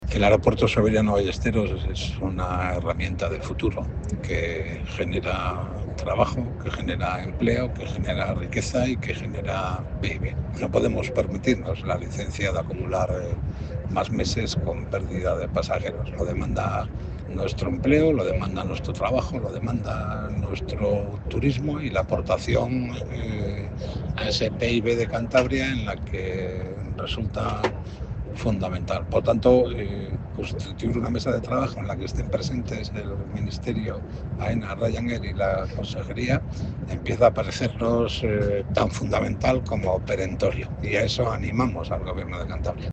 El diputado regionalista Javier López Marcano
Audio Javier López Marcano